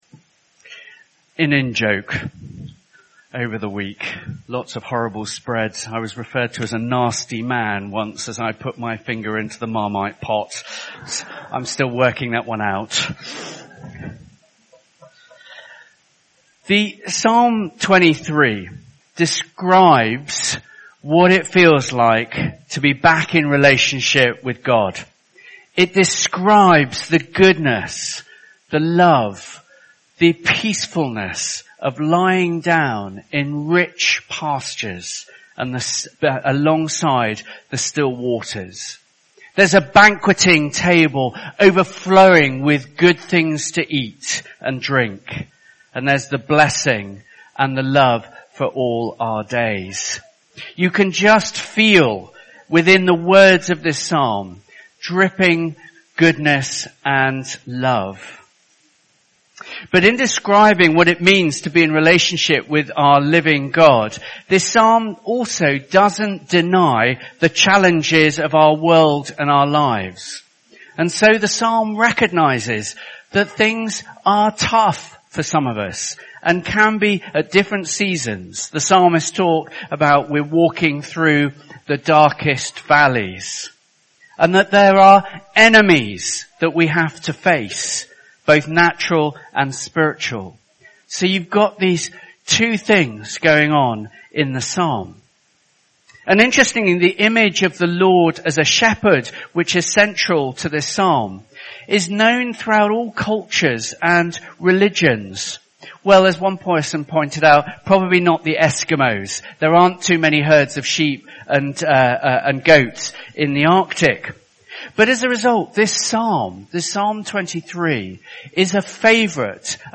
Holiday Club Joint Service – Psalm 23